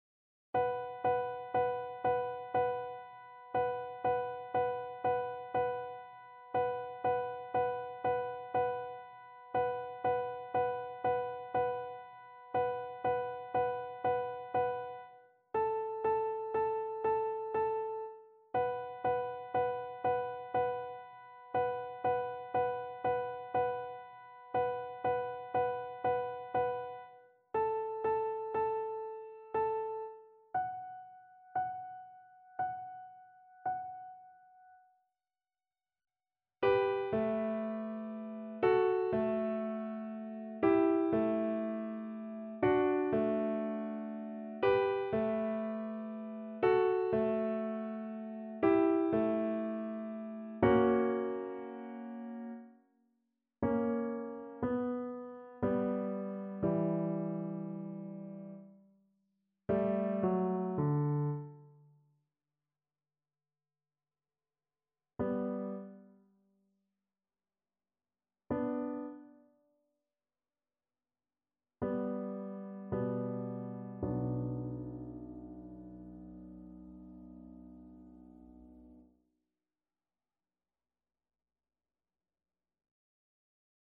Play (or use space bar on your keyboard) Pause Music Playalong - Piano Accompaniment Playalong Band Accompaniment not yet available transpose reset tempo print settings full screen
B minor (Sounding Pitch) (View more B minor Music for Cello )
Andante sostenuto =60
3/4 (View more 3/4 Music)
Classical (View more Classical Cello Music)